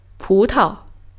pu2--tao.mp3